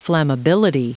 Prononciation du mot flammability en anglais (fichier audio)